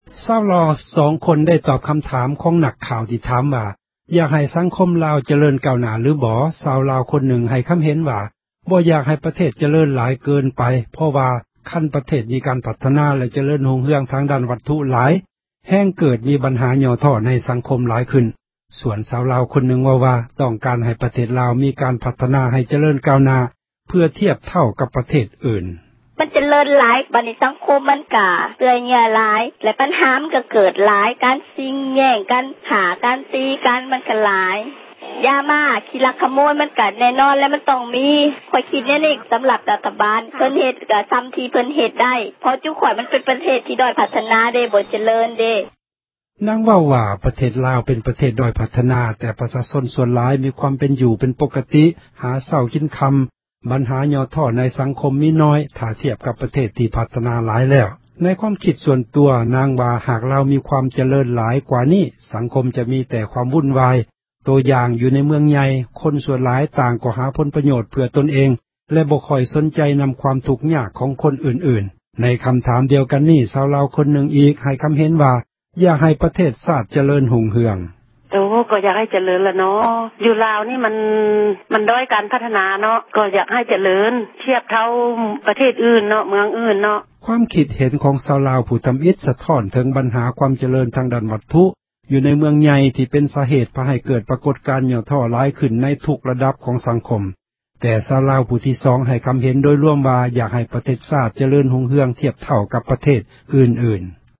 ຄົນລາວສອງຄົນ ໄດ້ຕອບ ຄໍາຖາມ ຂອງນັກຂ່າວ ທີ່ຖາມວ່າ ຢາກເຫັນ ປະເທດລາວ ຈະເຣີນ ກ້າວໜ້າຫລືບໍ່?